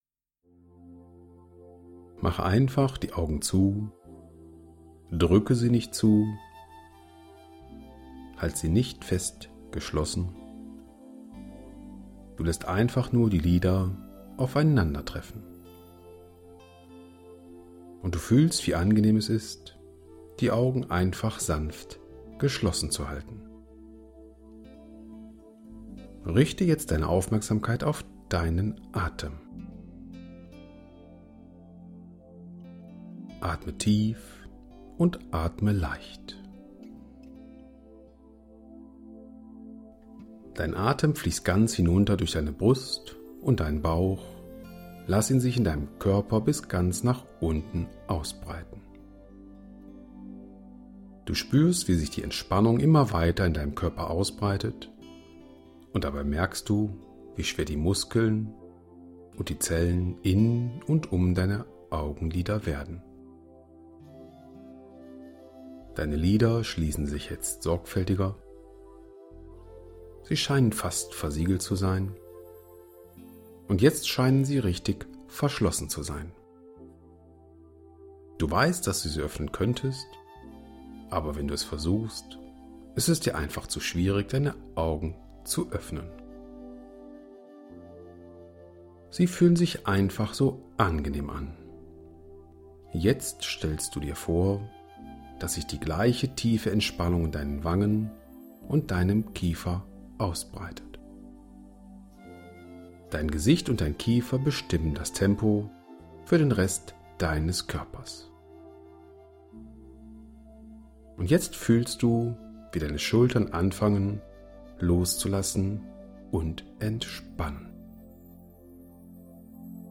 Genre: Vocal.